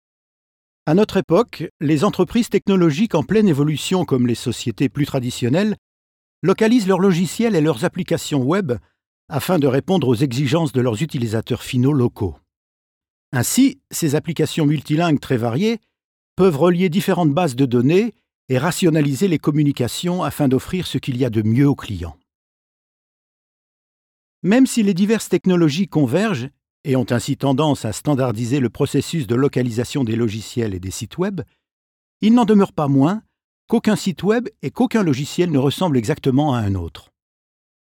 EQHO provides multi-language solutions from its in-house recording facilities
French Male 00985
NARRATION